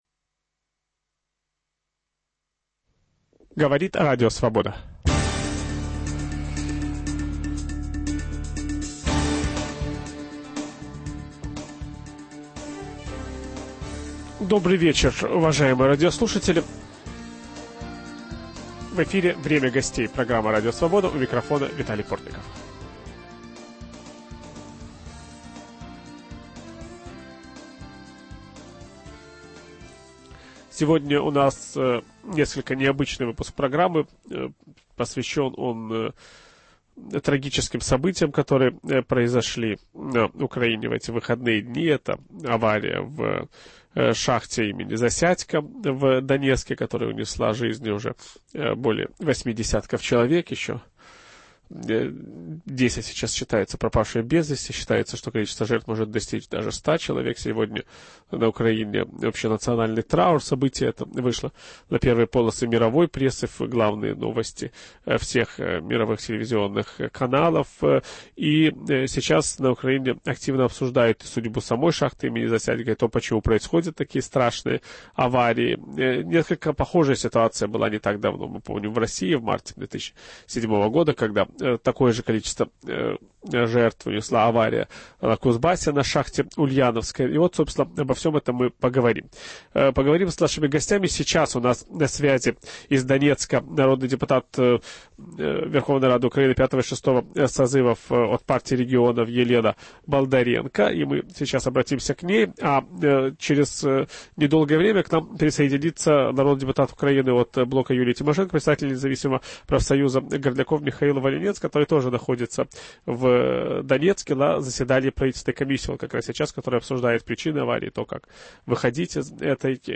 Сегодня на Украине прощаются с жертвами аварии на донецкой шахте имени Засядько. Ведущий программы Виталий Портников беседует о проблем украинских шахтеров с депутатами Верховной Рады Украины Анатолием Семиногой и Михаилом Волынцом